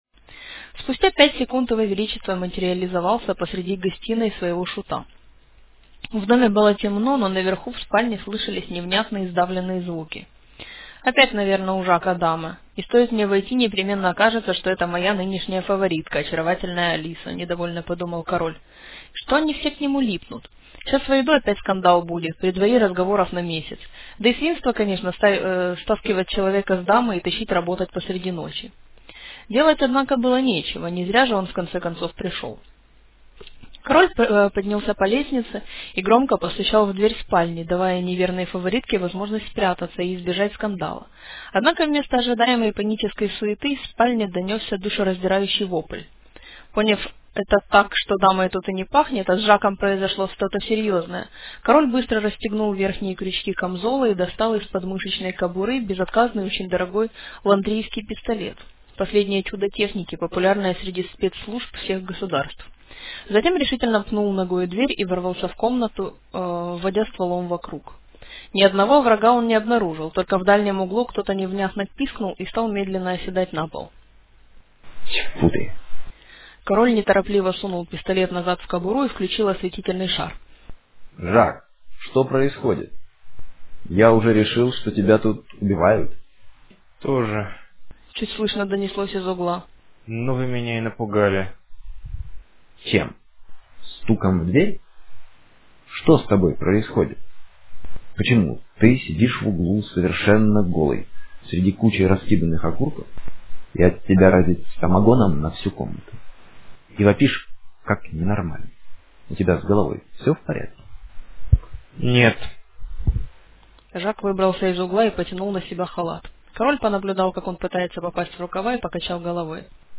вот ссылка на кусочек, озвученный разными голосами:
три диктора, аудиорежисер, все старались.
какие-то внятные претензии только к последнему (громкости реплик несколько несогласованы), актеры начитали вполне добросовестно - а суммарный результат напрягает мне слух... Почему- не знаю, могу только гадать...